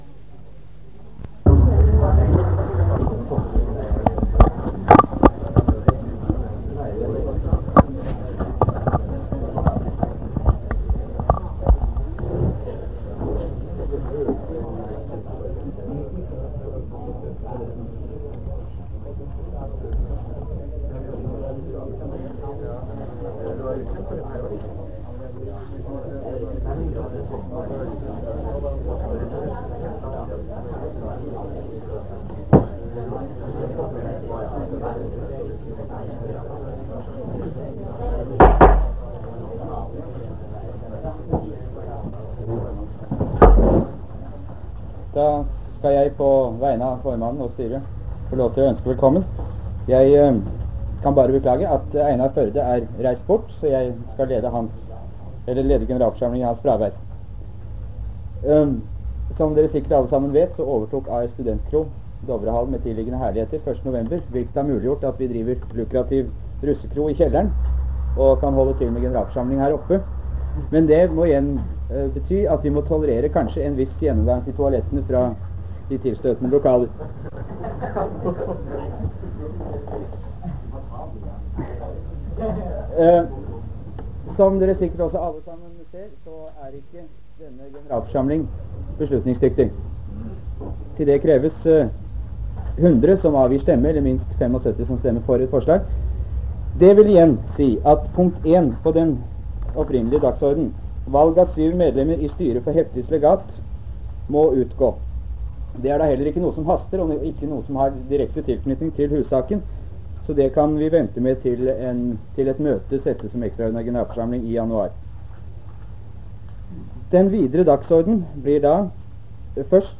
Det Norske Studentersamfund, Generalforsamlinger, Ekstraordinær generalforsamling, 06.12.1966